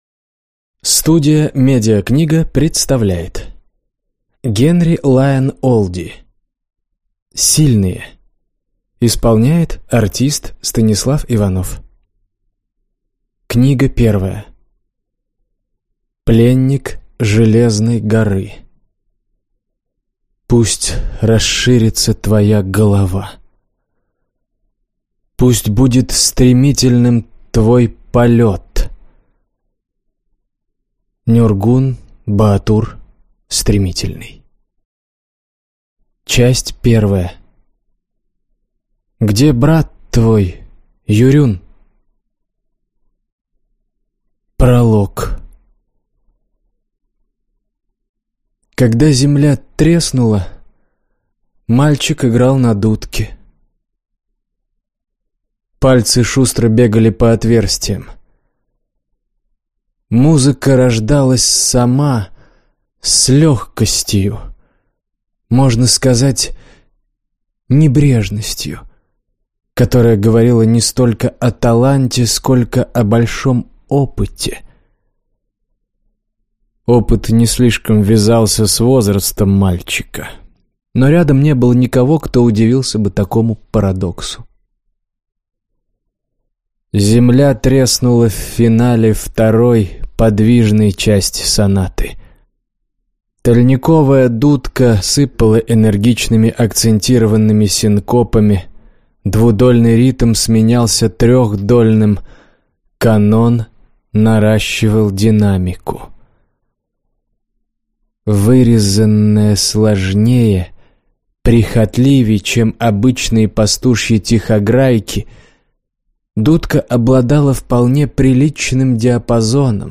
Аудиокнига Пленник железной горы - купить, скачать и слушать онлайн | КнигоПоиск